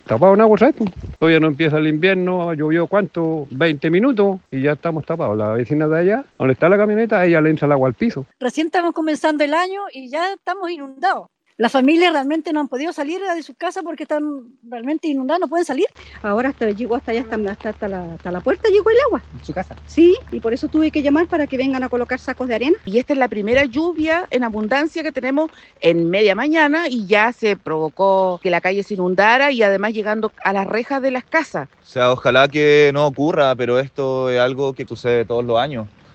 Así, al menos, lo hicieron saber a Radio Bío Bío vecinos de los sectores Martínez de Rozas, Pedro de Montoya o Gaspar de Ahumada, quienes acusaron que en 20 minutos de lluvia, las calles se vieron totalmente colapsadas.